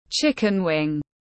Cánh gà tiếng anh gọi là chicken wing, phiên âm tiếng anh đọc là /ˈʧɪkɪn wɪŋ/
Chicken wing /ˈʧɪkɪn wɪŋ/